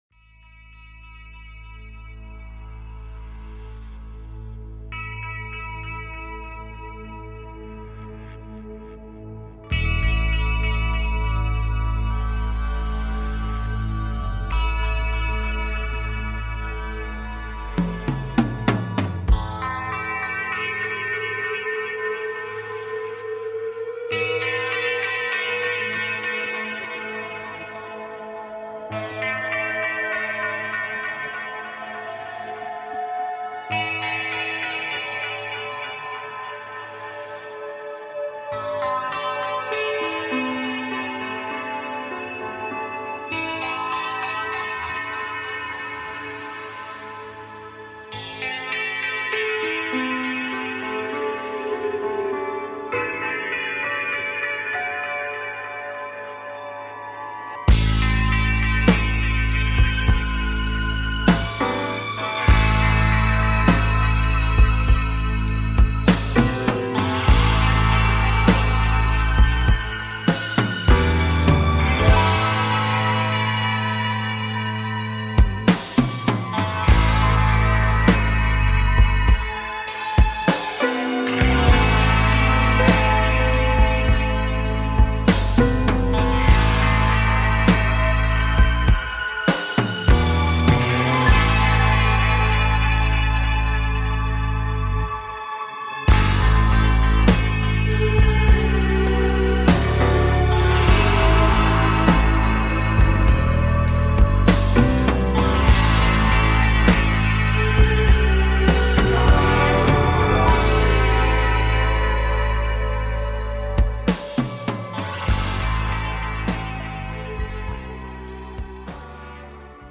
A show based on Timelines and manifesting Timelines while taking caller questions.